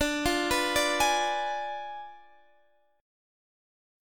Ddim7 Chord (page 2)
Listen to Ddim7 strummed